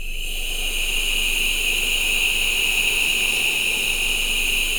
E-NOISEBED.wav